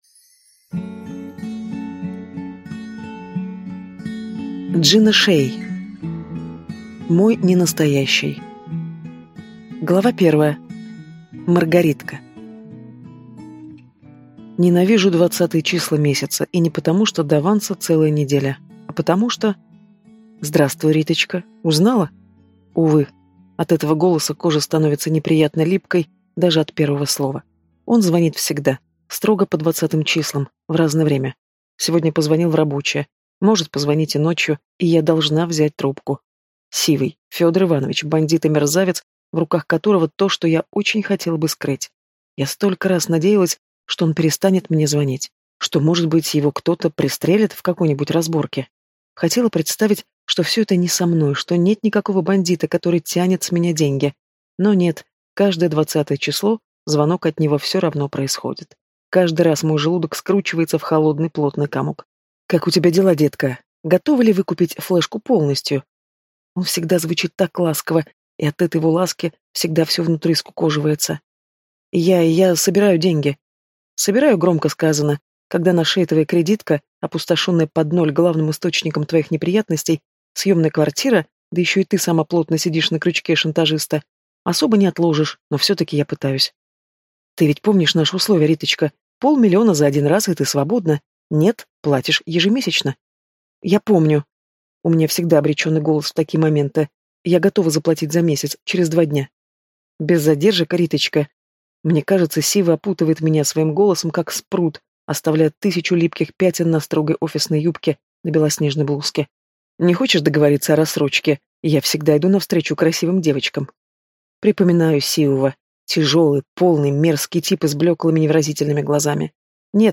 Аудиокнига Мой ненастоящий | Библиотека аудиокниг